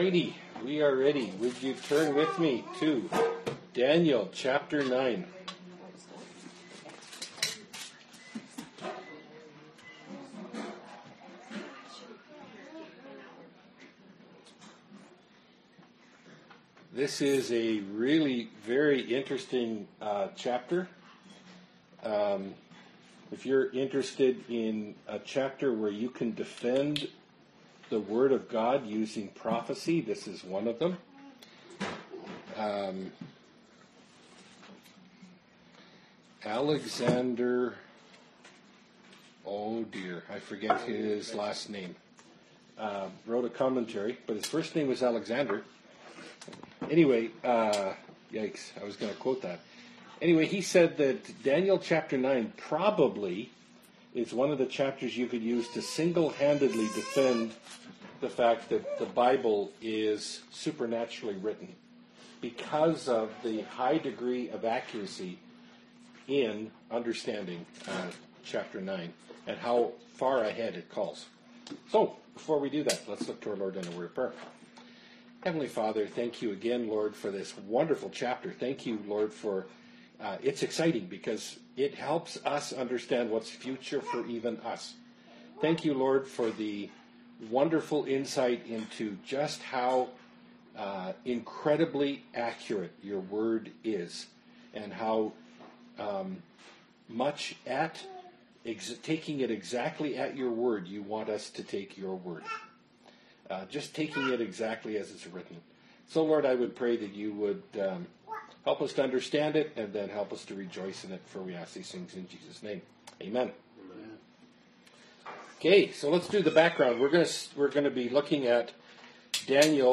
Bible Study – Daniel 9 – Part 2 of 3 (2017)
Category: Bible Studies Key Passage: Daniel 9